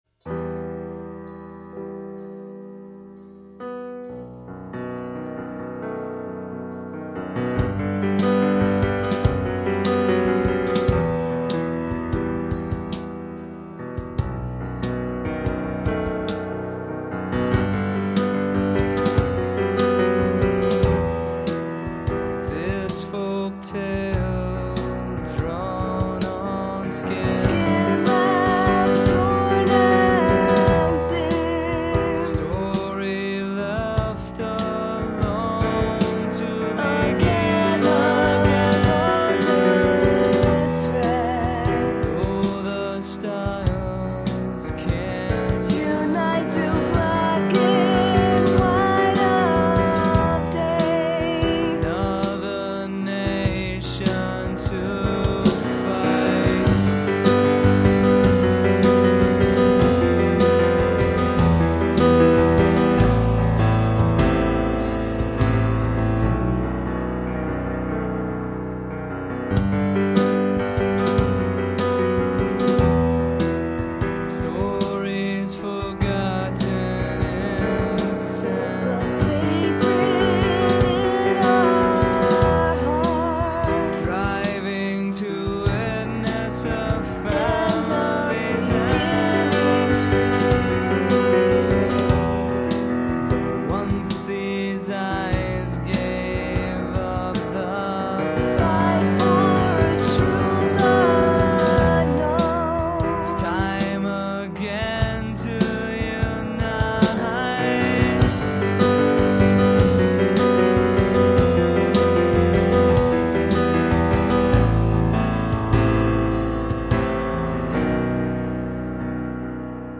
piano and drums
this duet had a sound much larger than its parts.